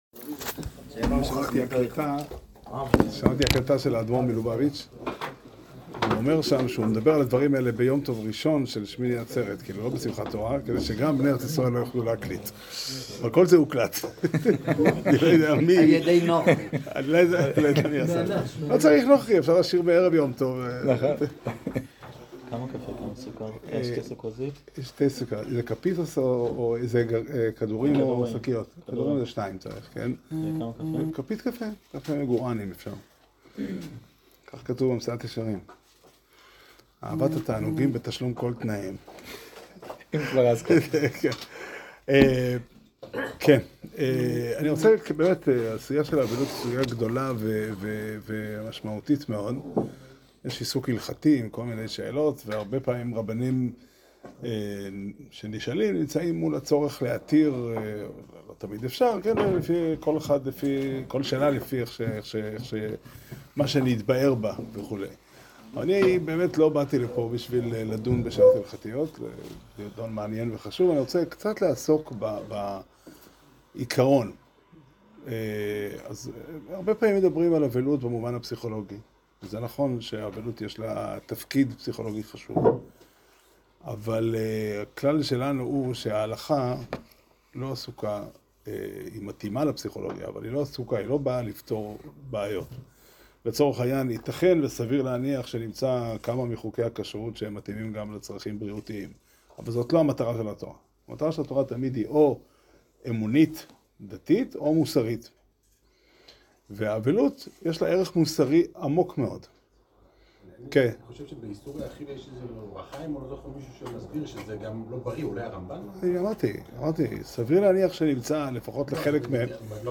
שיעור שנמסר בתאריך כ"ו אדר א' תשפ"ד